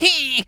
rabbit_squeak_hurt_02.wav